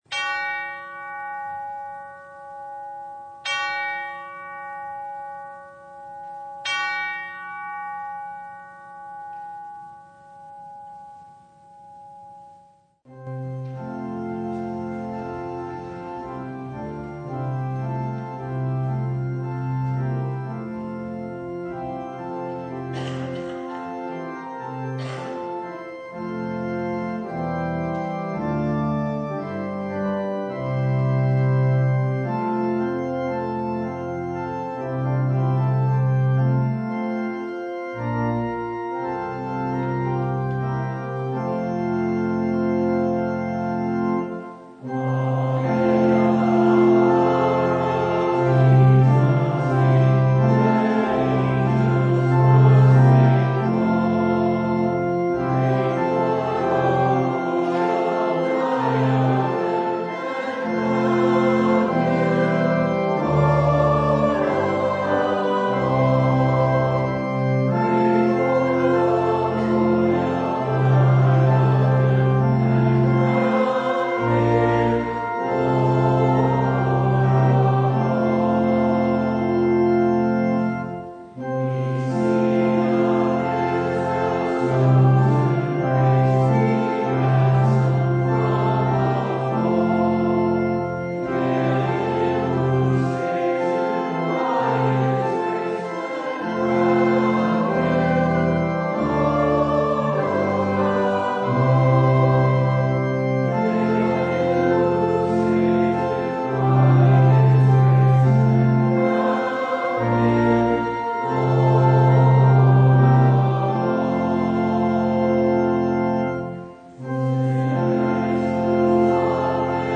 Preacher: Visiting Pastor Passage: Matthew 21:23-27
Download Files Bulletin Topics: Full Service « More than Wages—Grace!